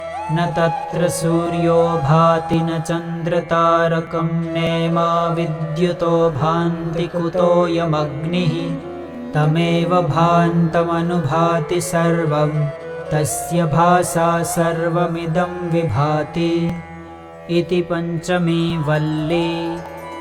Mantra